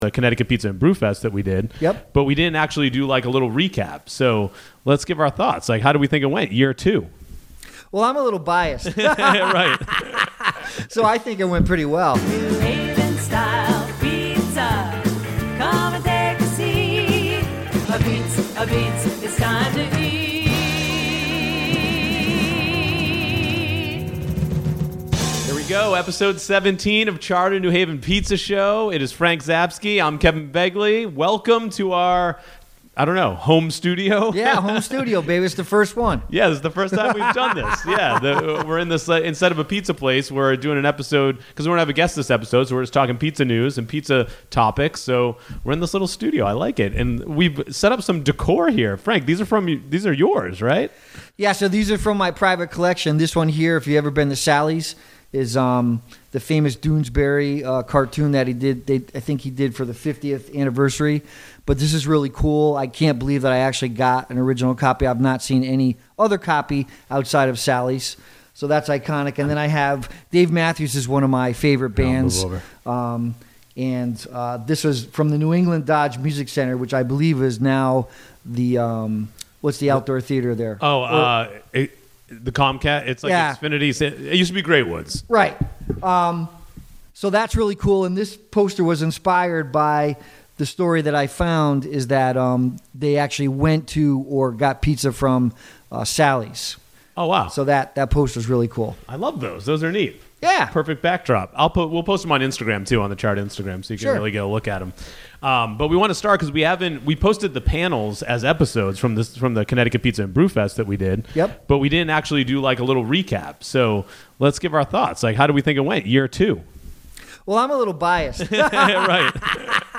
"in-studio" episode